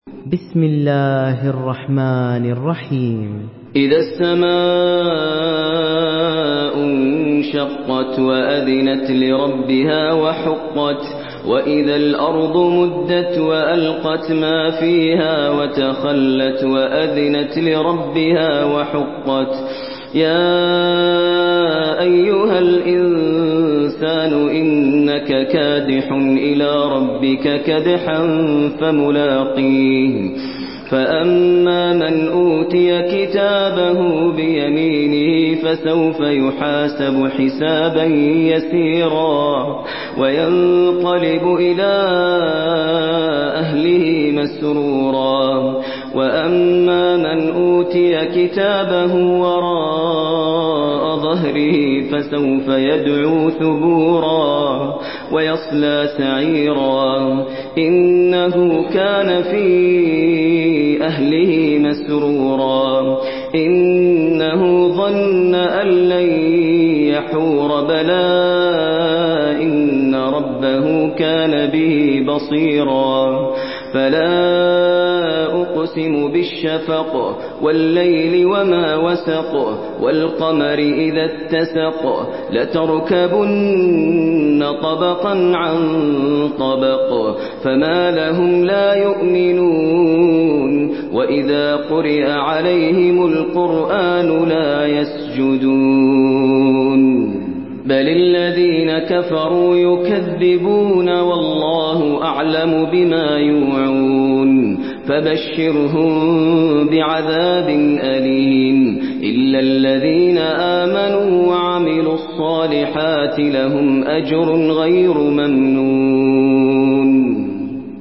سورة الانشقاق MP3 بصوت ماهر المعيقلي برواية حفص
مرتل